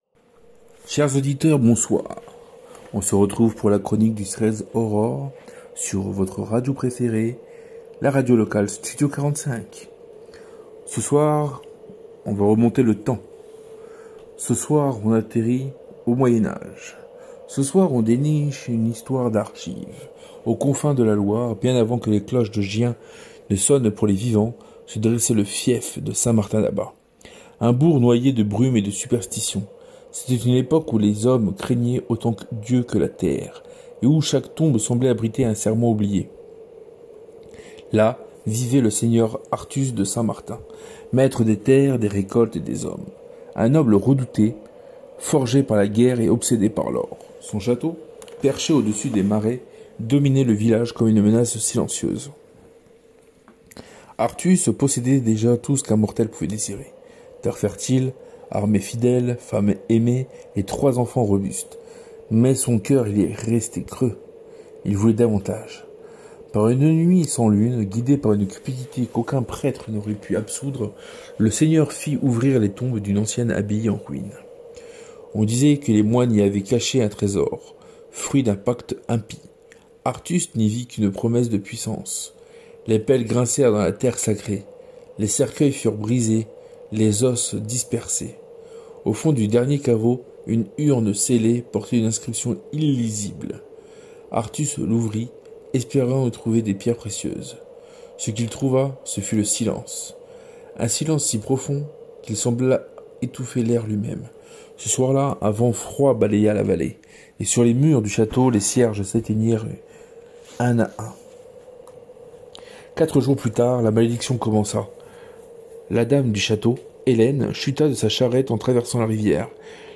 La Légende d’Arthus — Une épopée sonore signée Studio 45
À travers une réalisation immersive et des ambiances travaillées, Studio 45 vous invite à vivre une expérience d’écoute unique — entre cinéma pour les oreilles et conte moderne.Une aventure à la croisée des temps, où l’imaginaire reprend toute sa place.